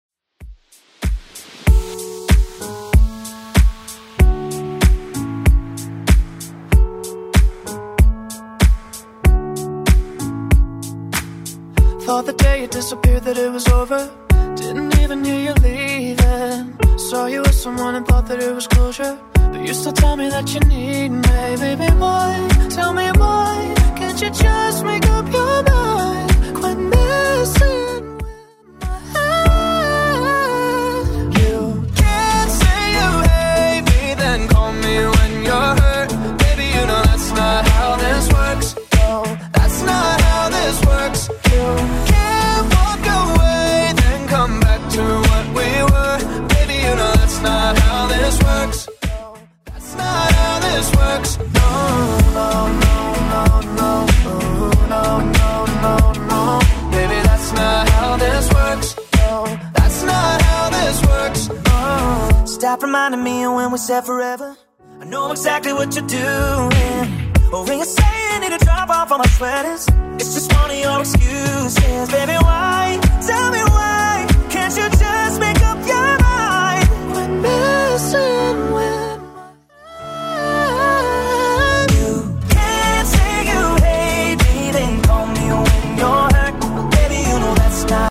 BPM: 95 Time